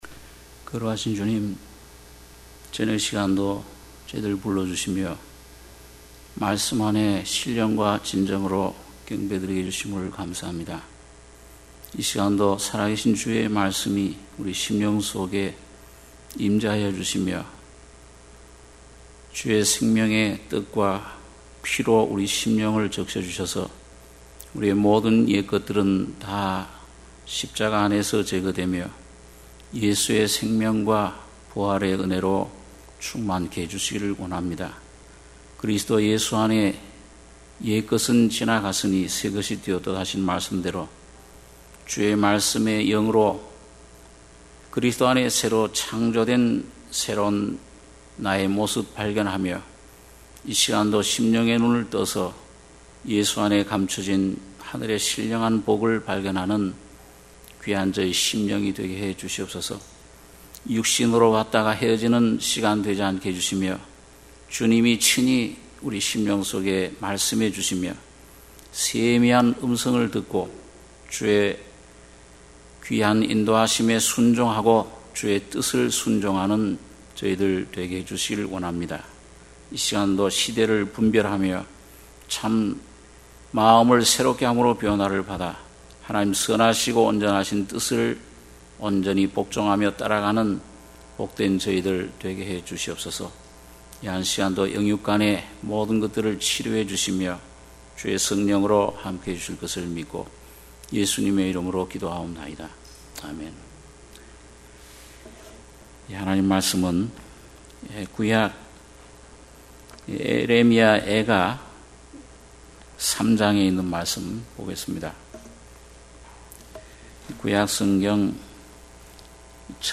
수요예배 - 예레미야 애가 3장 17-26절